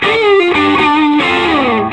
Index of /90_sSampleCDs/USB Soundscan vol.22 - Vintage Blues Guitar [AKAI] 1CD/Partition C/04-SOLO D125